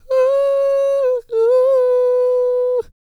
E-CROON P331.wav